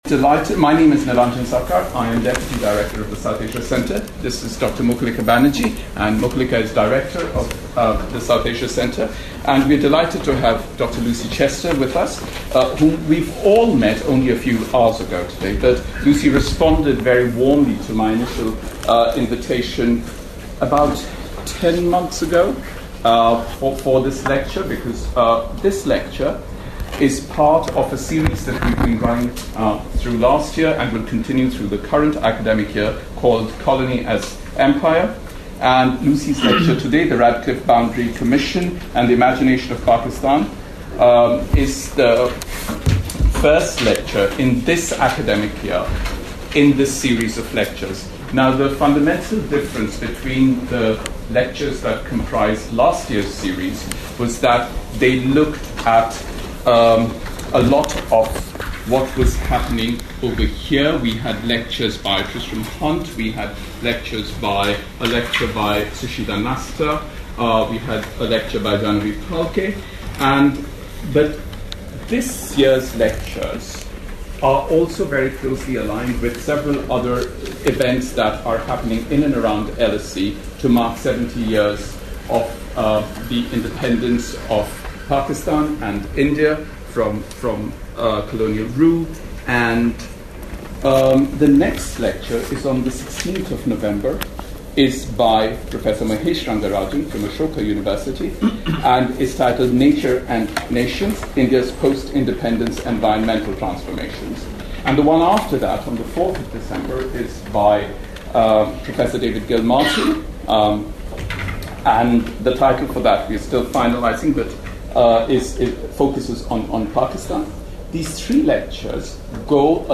This is a South Asia Centre public lecture which is part of the Colony as Empire: Histories from Whitehall series.